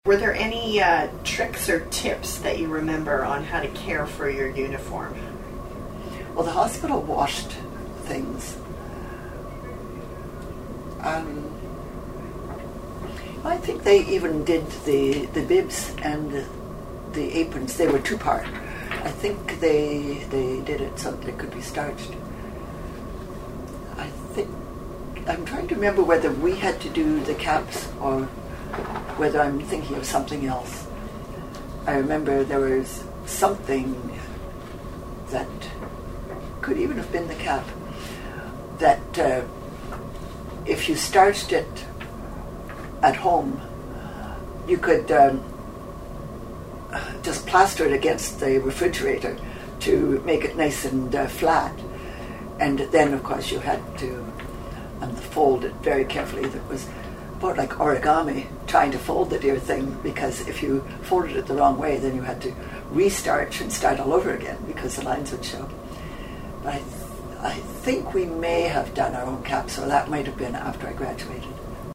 Audio Credits: From the collection of The Miss Margaret Robins Archives of Women’s College Hospital.